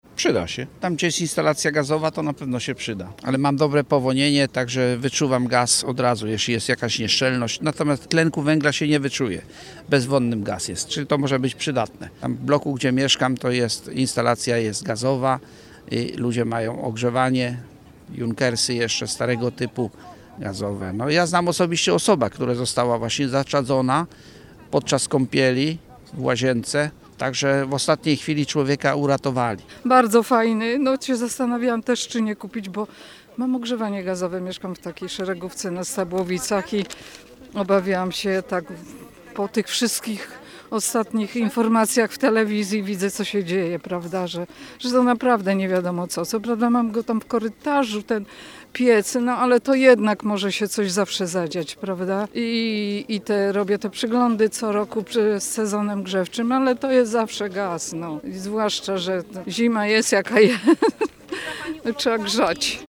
Jak na akcję reagują sami wrocławianie? Osoby, które odebrały dziś bezpłatne czujki, mówią wprost: to niewielkie urządzenie daje realne poczucie bezpieczeństwa, szczególnie w starszych budynkach z instalacją gazową.
mieszkancy.mp3